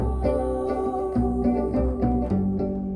Distorted sound coming from computer speakers....
Upon re-listening i have discovered that the track has some distortion quality when played back on my laptop speakers, but not at all on the headphones.
I think there is fizzy middle frequency crackle which the bass (and treble) covers up when heard in speakers that can reproduce a greater range of frequencies.
I do hear some “clicking” around the middle of the song, but to me that sounds “real”… An acoustic noise that the microphone picked-up rather than a recording artifact.
IMO - the bass is not too strong.
It is very “quiet”, but it’s quiet acoustic music!